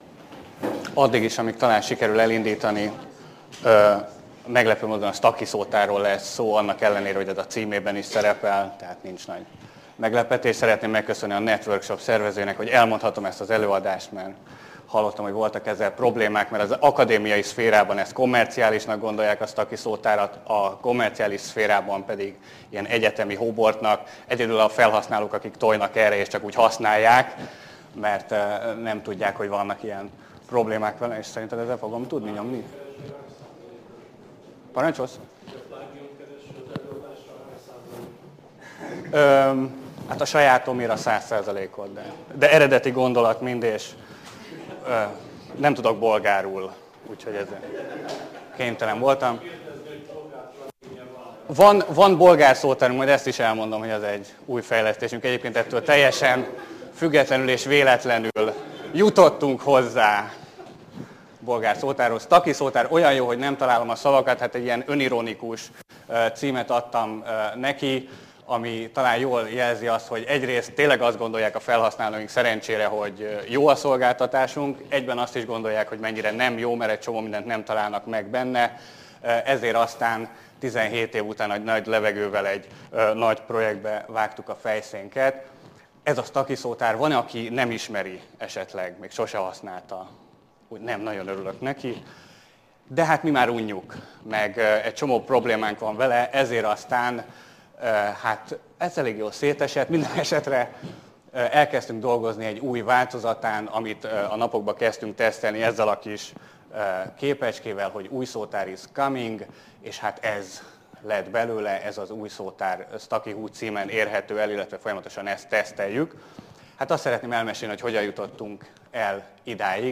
Csatornák Networkshop 2012 konferencia